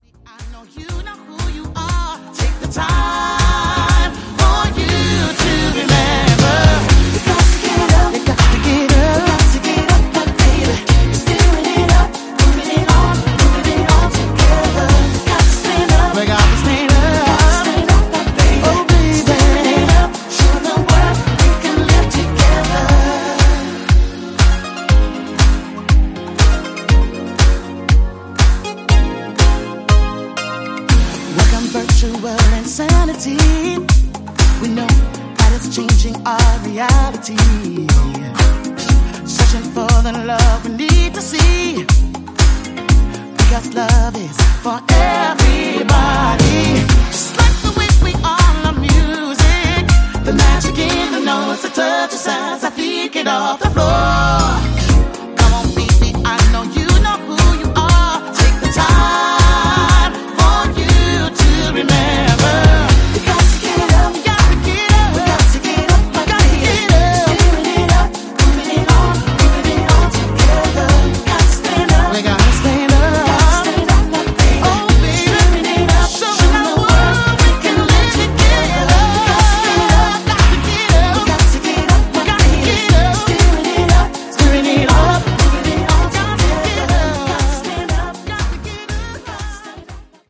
王道系のヴォーカル・ハウス好きはぜひ！